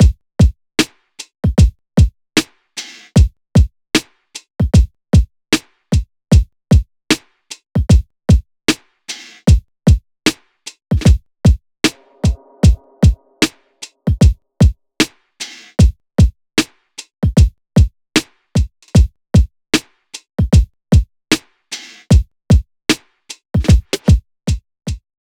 LoFi Archives
AV_Loft_Drums_152bpm
av_loft_drums_152bpm